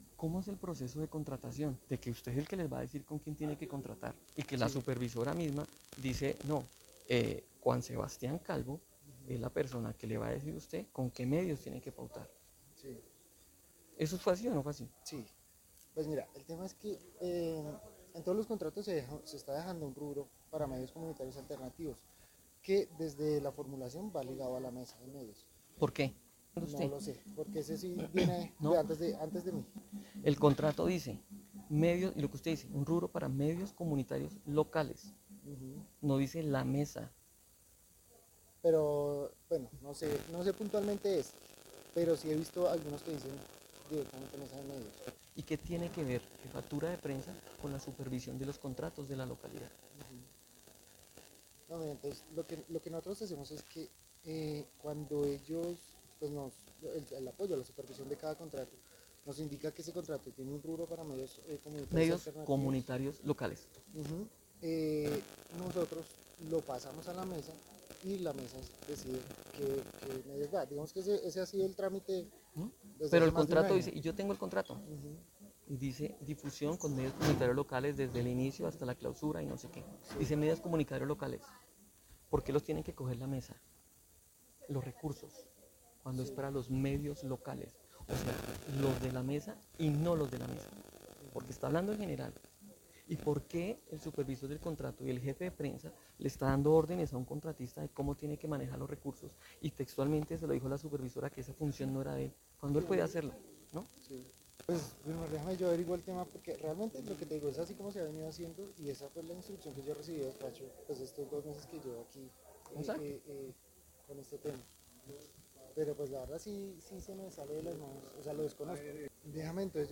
En una entrevista exclusiva para Valaguela